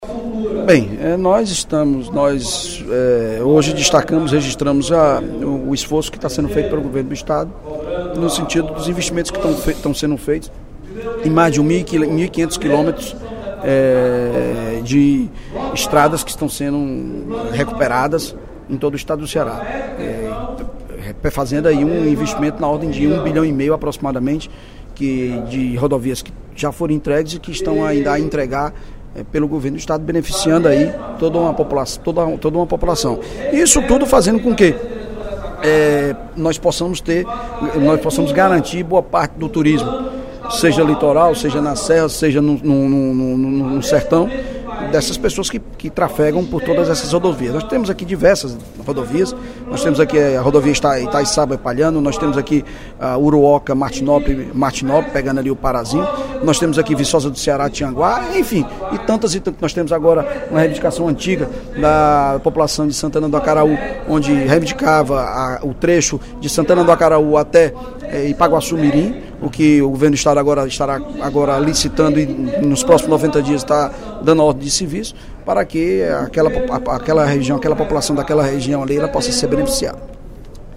O líder do Governo na Assembleia Legislativa, deputado Evandro Leitão (PDT), destacou, no primeiro expediente da sessão plenária desta quarta-feira (02/03), investimentos realizados em infraestrutura pelo Executivo Estadual, com destaque para a construção e reforma das rodovias cearenses.